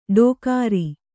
♪ ḍōkāri